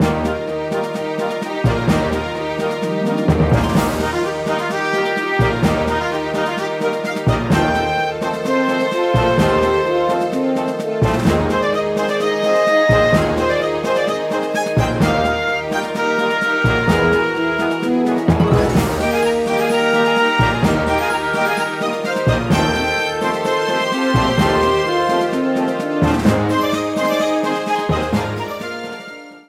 Ripped from the game files
applied fade-out on last two seconds when needed